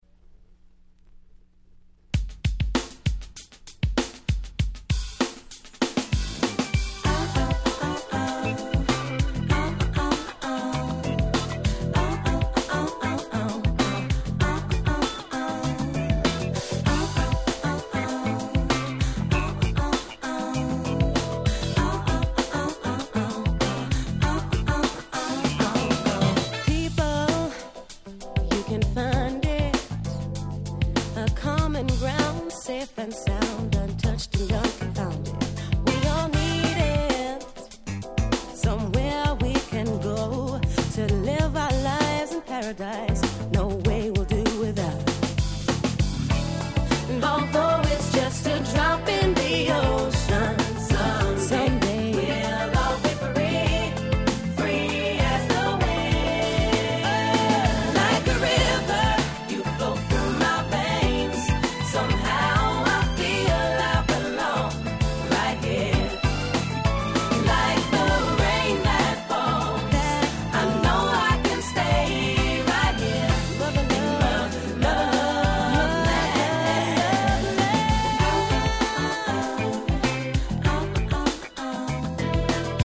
96' Nice R&B LP !!